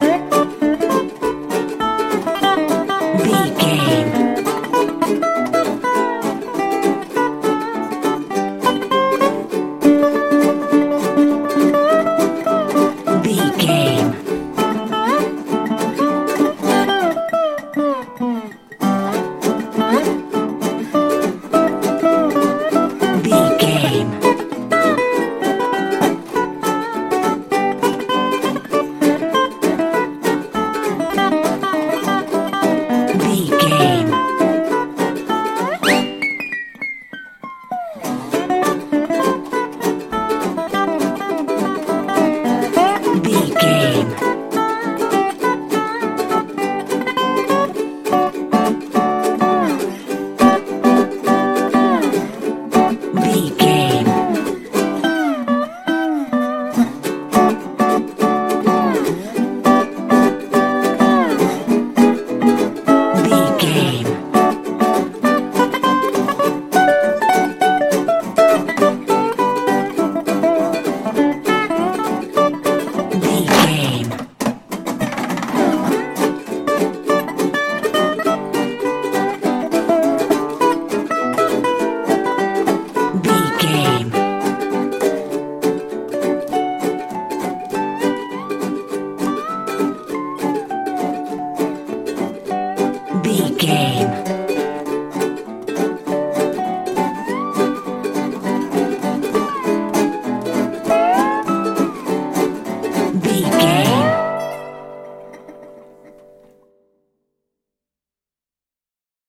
Ionian/Major
electric guitar
acoustic guitar
drums